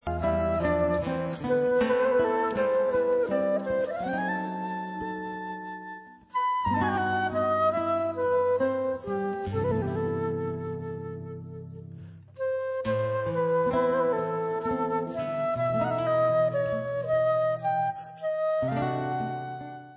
LIVE IN BRUSSELS